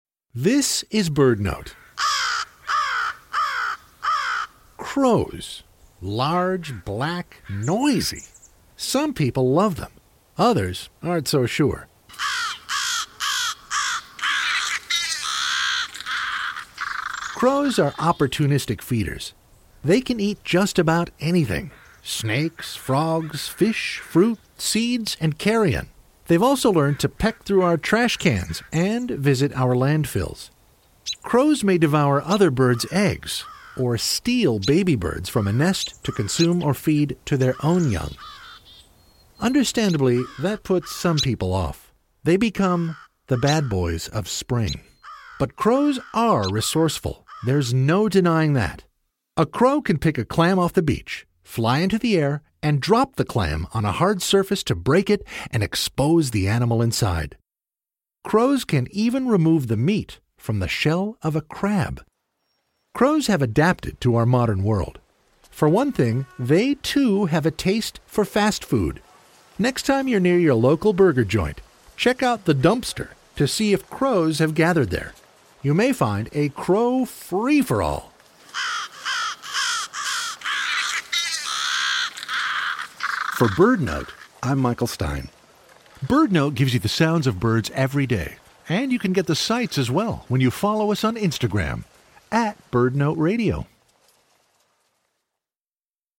Large, black, noisy. The raucous birds of the neighborhood.